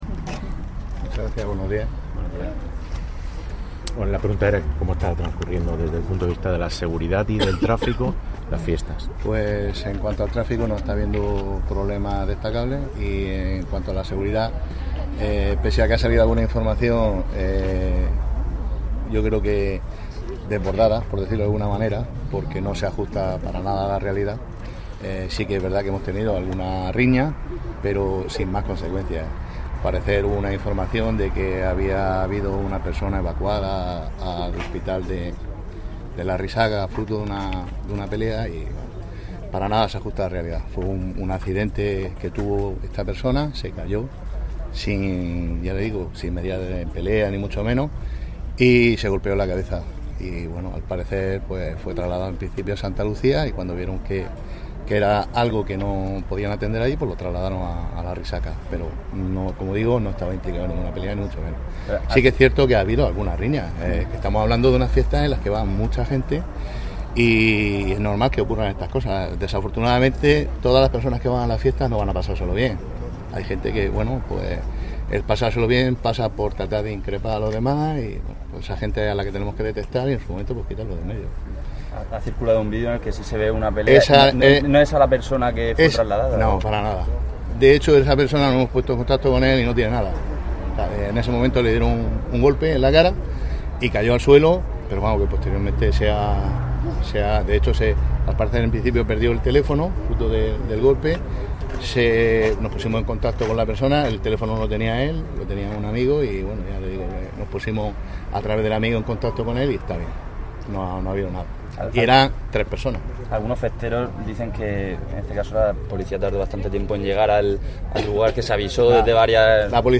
Enlace a Declaraciones José Ramón Llorca.